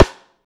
high rim shot ff.wav